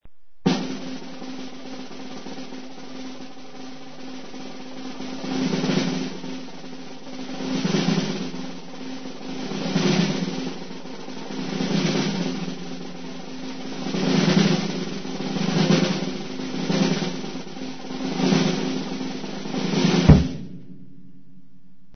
REDOBLE DE TAMBOR EN EL CIRCO
Tonos EFECTO DE SONIDO DE AMBIENTE de REDOBLE DE TAMBOR EN EL CIRCO
Redoble_de_tambor_en_el_circo.mp3